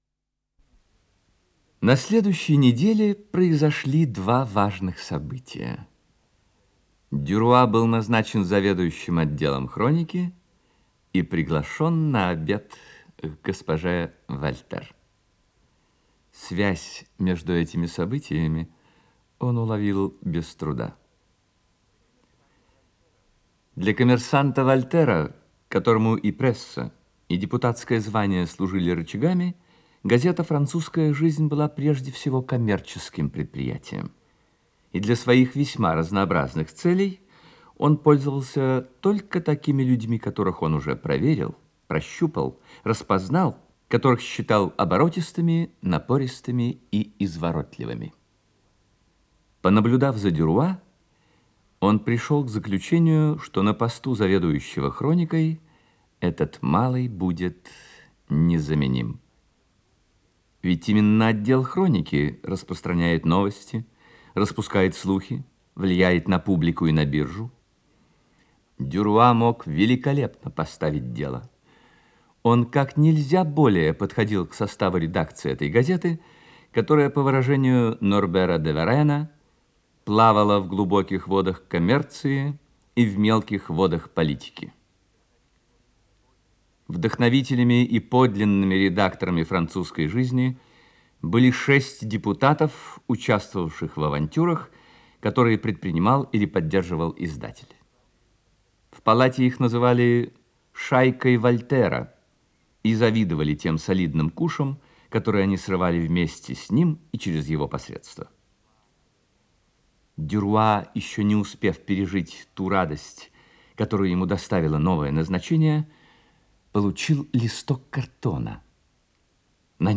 Исполнитель: Юрий Яковлев - чтение
Роман, передача 1-я